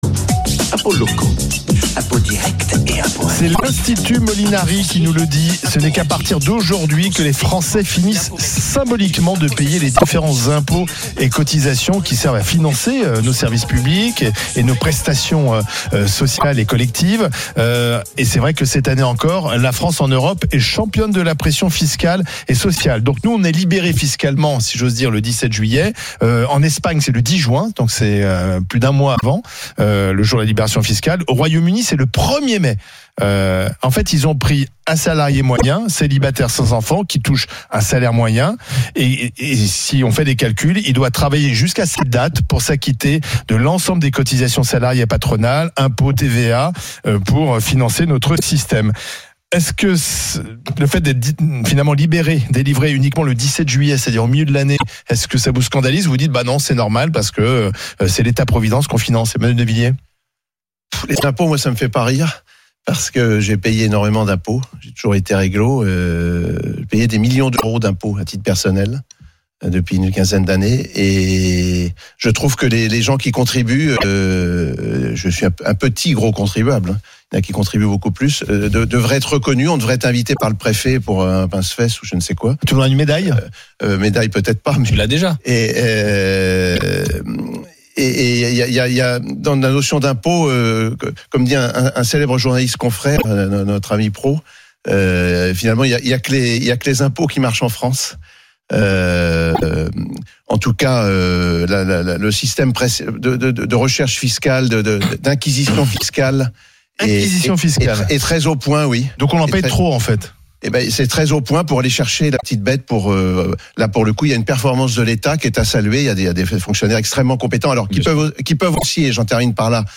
Une présentation des principaux résultats et des échanges avec les chroniqueurs et auditeurs.